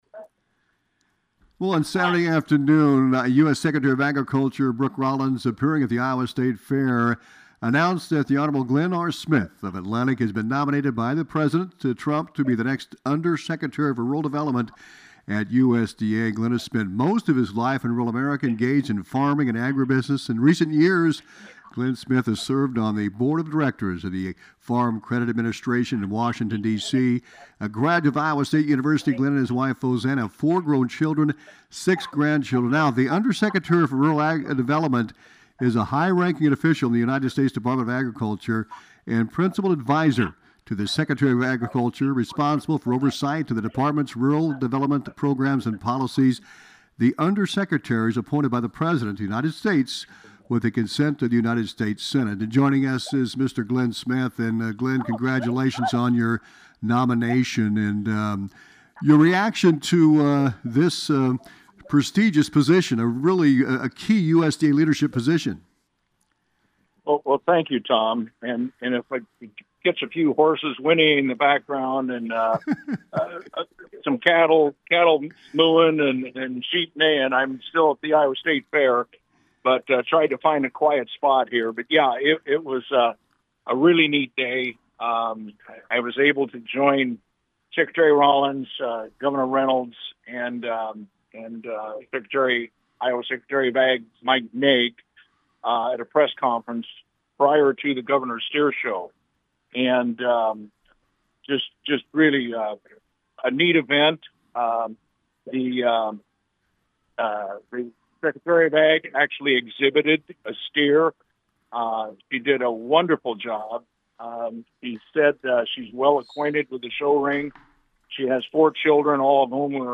glen-smith-interview.mp3